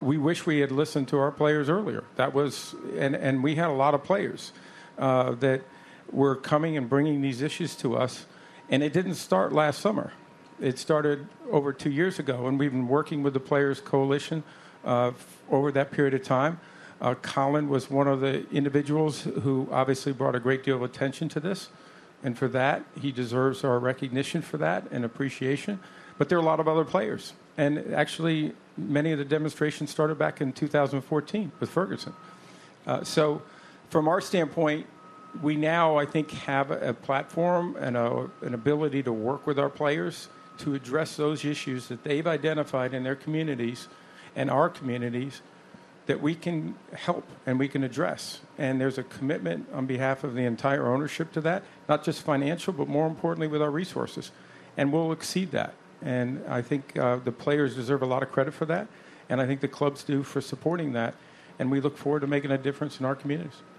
The annual State of the NFL news conference took place this Thursday by NFL Commissioner Roger Goodell.